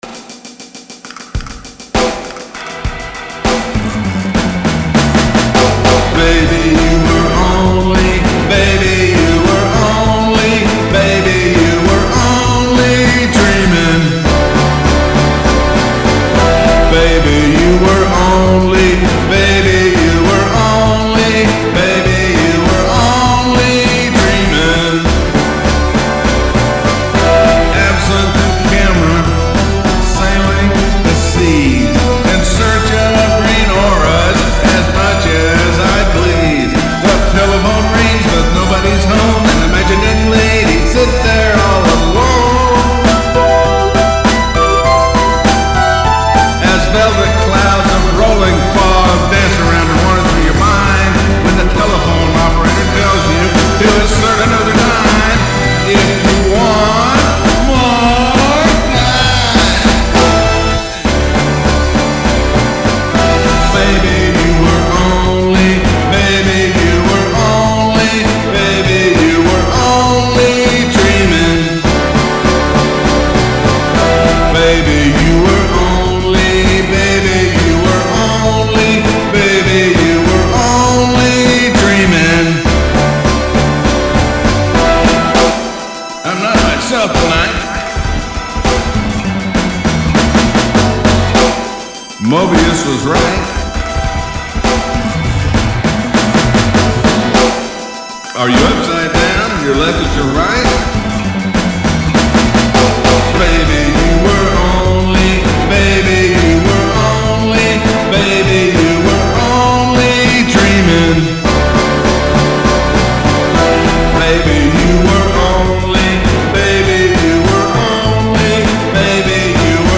As an example, the instruments for "(Baby You Were Only) Dreaming" are IK Multimedia VSTi virtual instruments played via music notation in NOTION 3 (32-bit), as were the IK Multimedia AU effects plug-ins I used in Digital Performer 7.24 (MOTU), which also is 32-bit only, but over the weekend I made a copy of the project and opened the copy in Digital Performer 8.01, followed by updating the effects plug-ins and then remixing the song, starting with a few basic instruments and then expanding to the fancy stuff, which included in some instance using different effects plug-ins or changing the setting for the 64-bit versions that replace the original 32-bit versions, and it took approximately 12 hours to do the remix, since there are a lot of instruments and I added a special effects plug-in (Pro-C [FabFilter Software Instruments]) that does "ducking" to quite a few of the instrumental tracks, which makes space available when there is singing .